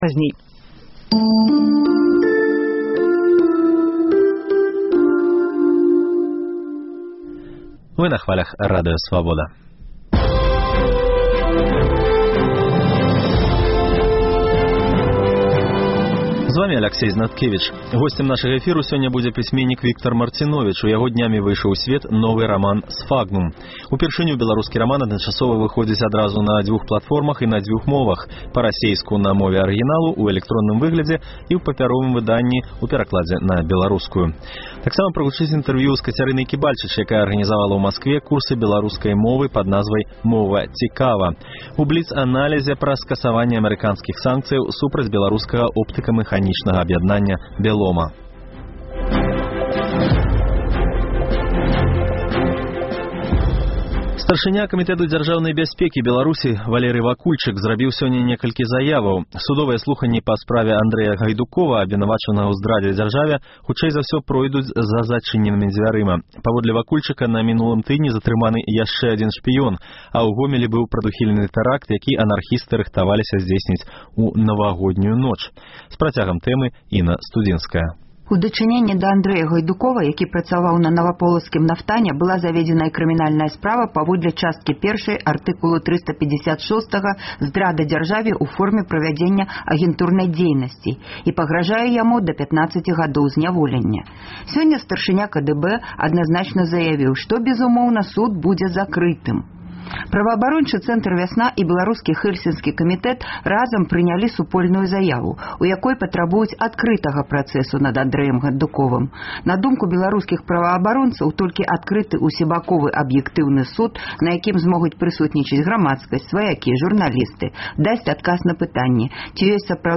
Госьцем нашага эфіру будзе пісьменьнік Віктар Марціновіч, у якога днямі выйшаў ў сьвет новы раман «Сфагнум».
інтэрвію